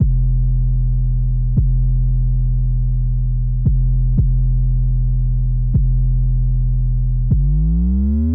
描述：这是我编写的令人毛骨悚然的万圣节类型的节拍，叫做"不要回头看quot。
Tag: 115 bpm Trap Loops Drum Loops 1.40 MB wav Key : C